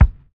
DISCO 2 BD.wav